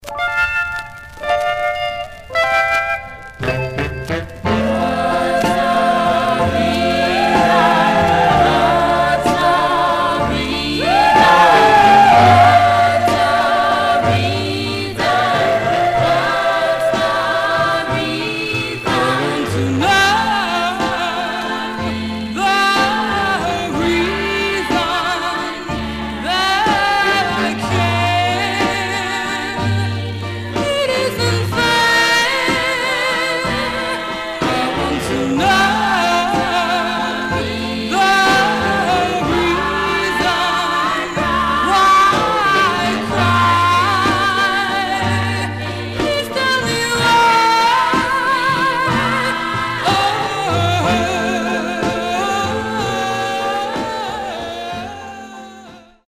Stereo/mono Mono
Black Female Group Condition